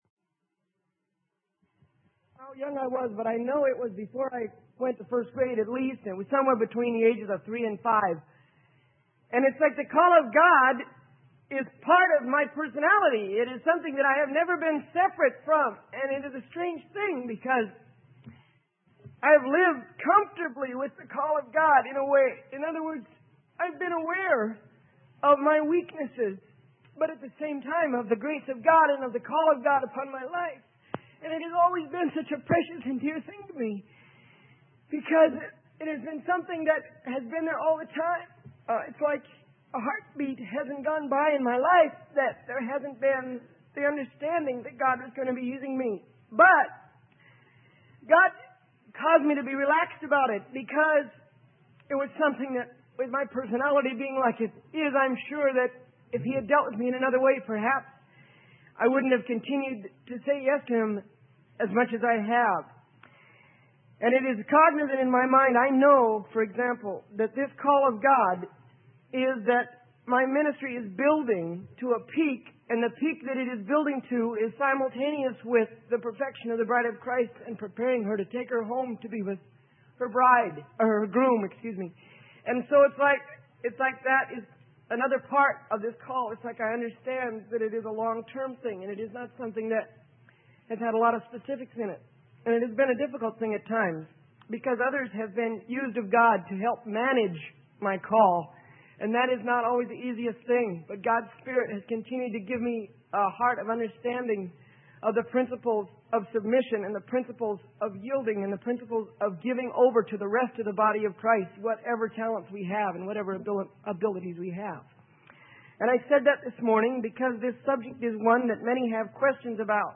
Sermon: The Doctrine of Typology - Freely Given Online Library